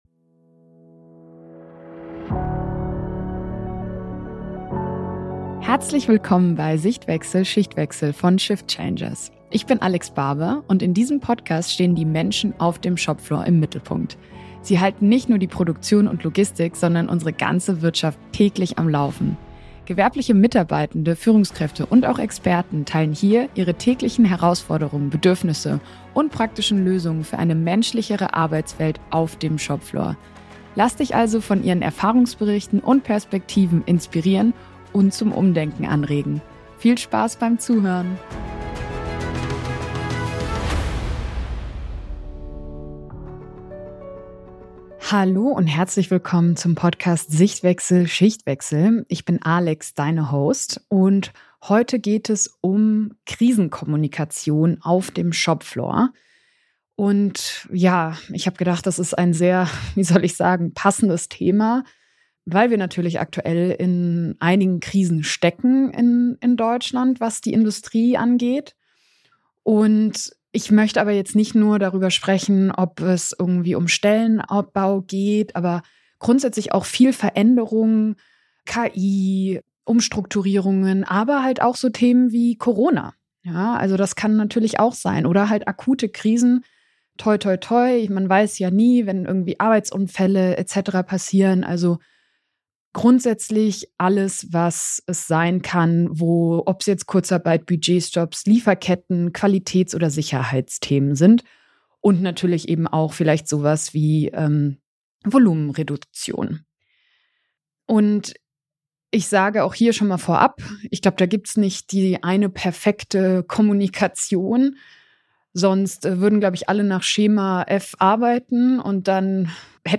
In dieser Solofolge teile ich meine persönlichen Erfahrungen und gebe praktische Prinzipien für eine wirkungsvolle Krisenkommunikation in Produktion und Logistik.